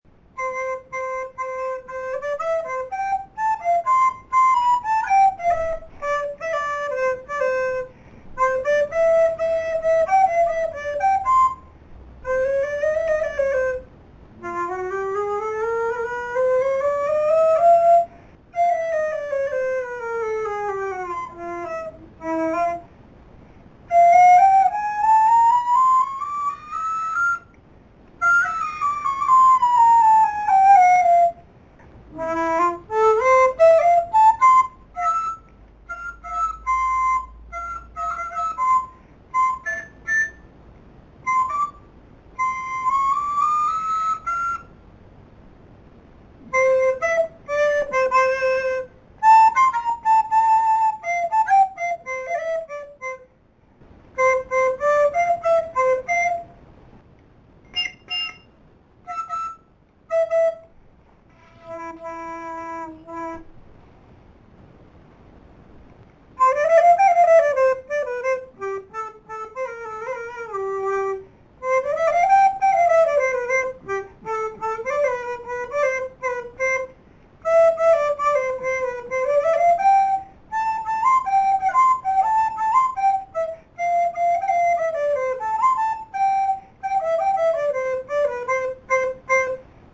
Fully keyed Supercorder & Grand Piano, live recording July 2007.
Sound Sample of this instrument
(Presented with apologies for the lack of talent and poor recording quality.